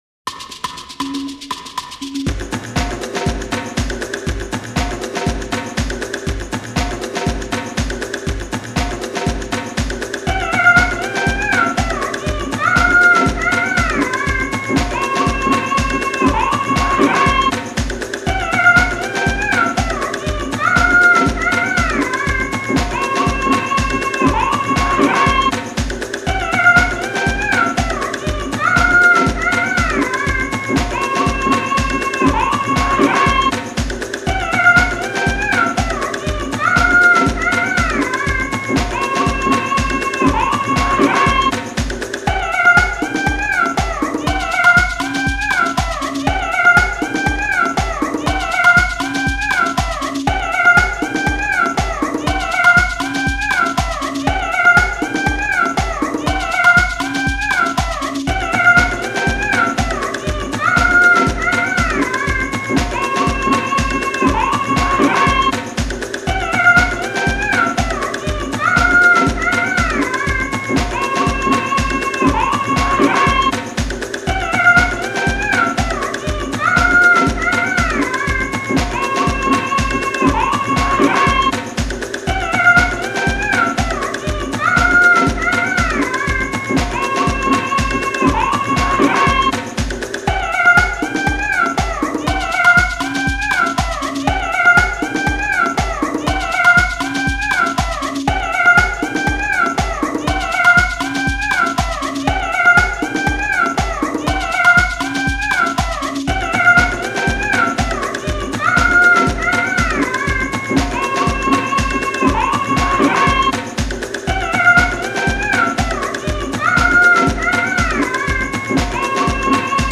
Genre: Native American.